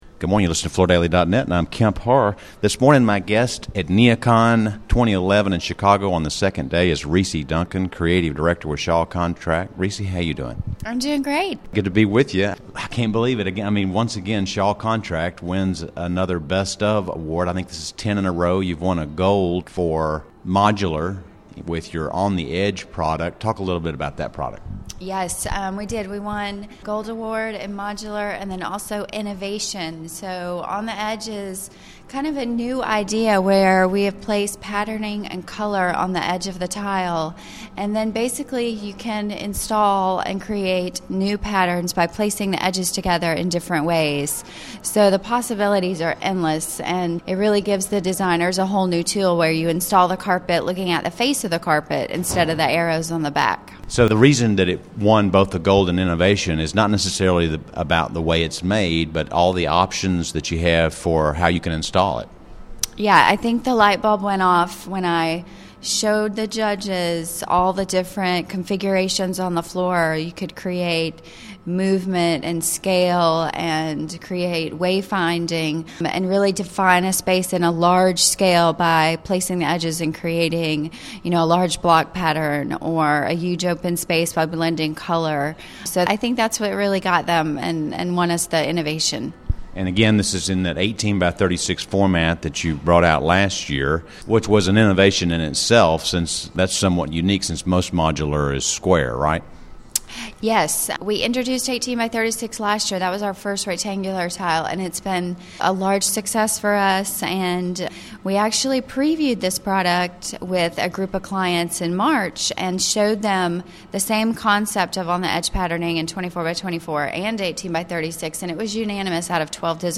Interviews with all segments of the flooring industry including; suppliers, manufacturers distributors & retail flooring stores.